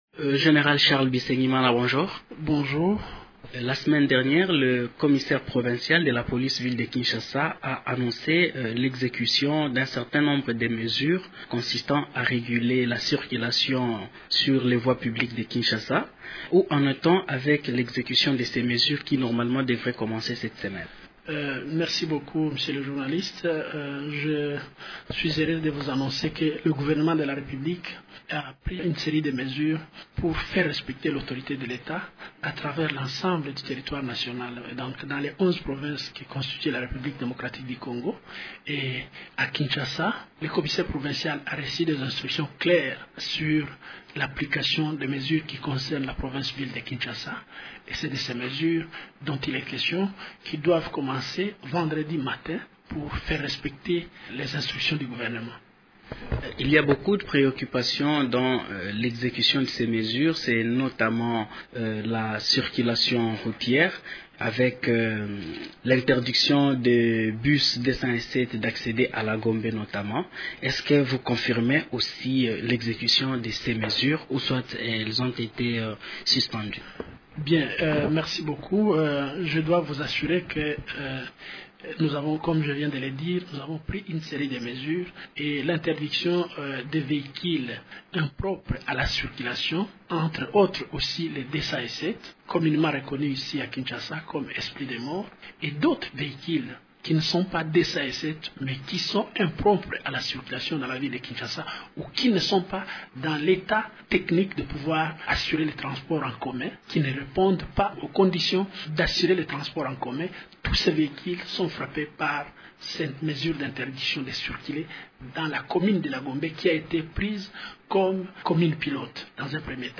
Le chef de la police commente ces mesures au micro